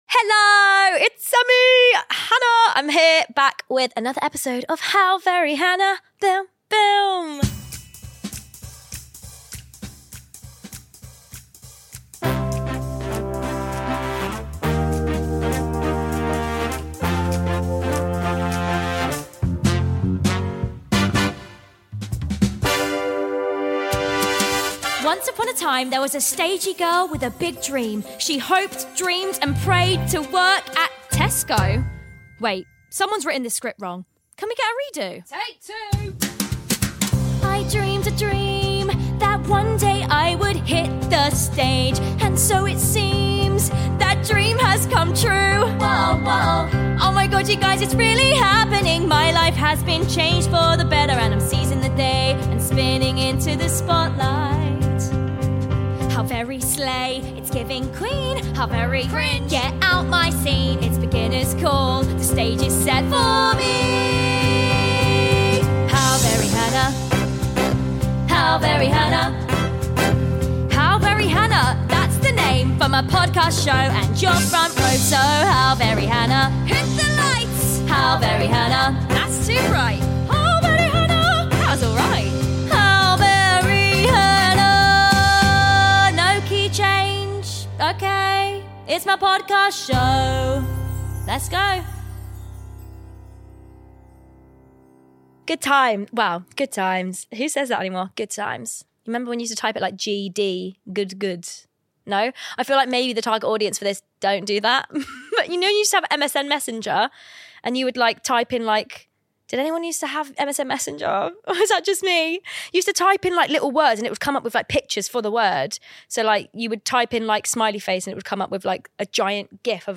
WE’VE GOT YOUTUBE ROYALTY IN THE STUDIO 👑✨